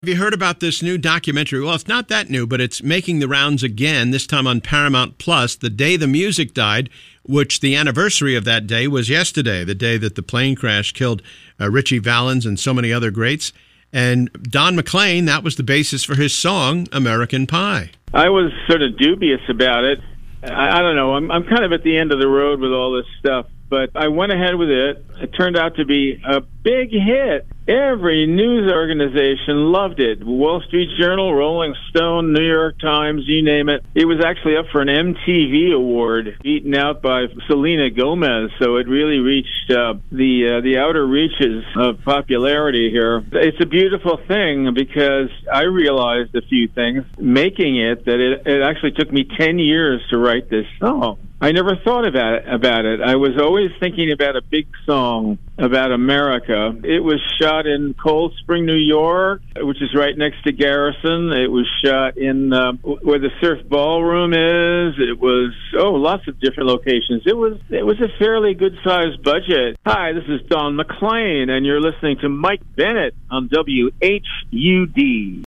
“American Pie” singer/songwriter Don McLean talks about the Paramount+ documentary “The Day The Music Died” 2-4-25